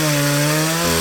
DayZ-Epoch/SQF/dayz_sfx/chainsaw/running1.ogg at ff766eafa134a2da21d621e2d0a3e1190dbbddd4